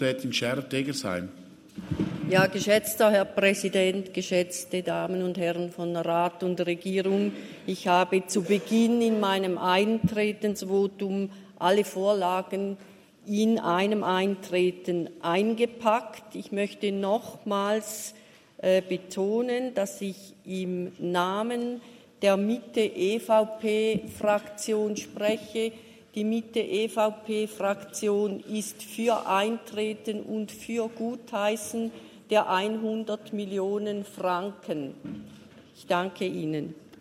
30.11.2022Wortmeldung
Session des Kantonsrates vom 28. bis 30. November 2022